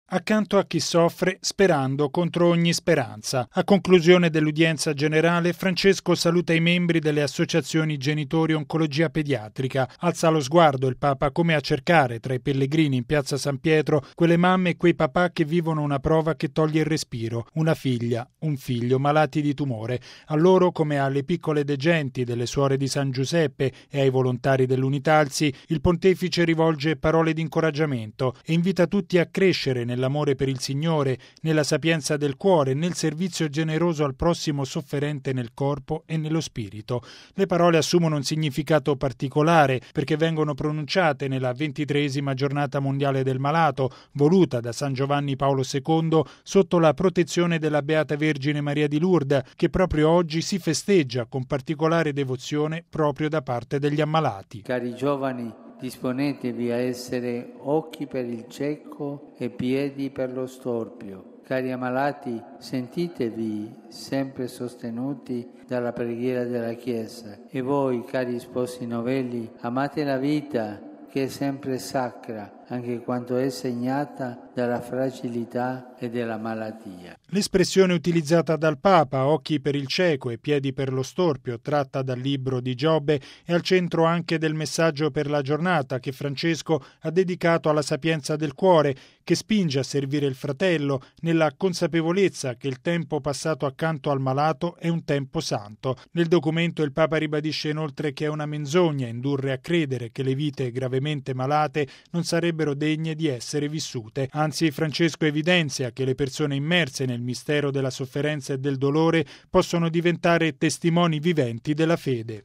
Al termine dell’udienza generale, nell’odierna 23.ma Giornata Mondiale del Malato, Papa Francesco ha rivolto un pensiero ai malati, affidandoli alla Beata Vergine di Lourdes di cui ricorre oggi la memoria.